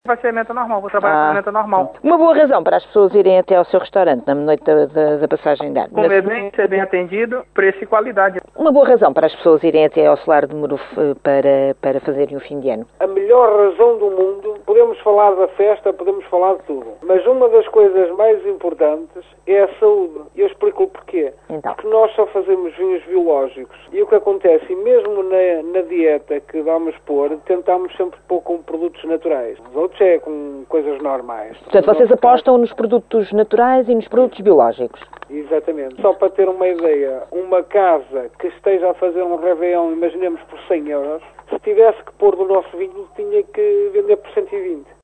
A Rádio Caminha saiu à rua para tentar perceber o que está a ser preparado pela restauração da região.